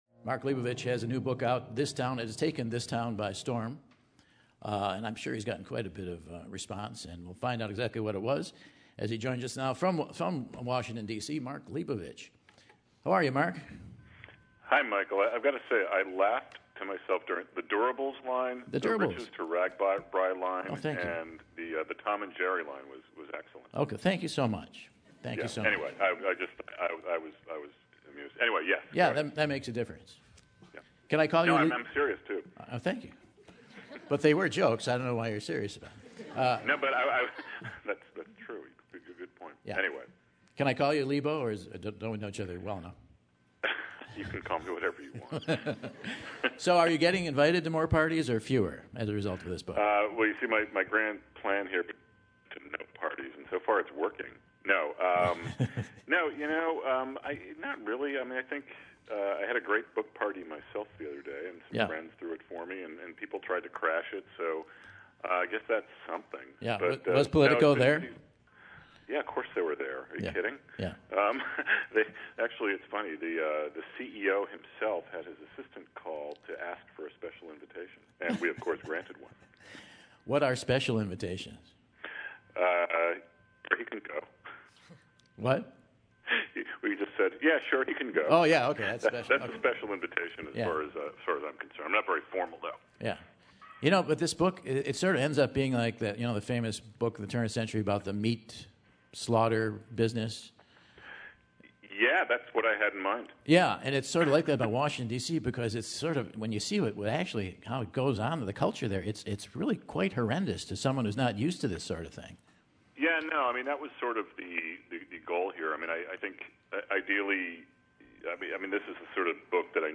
Author and New York Times writer Mark Leibovich joins Michael on air!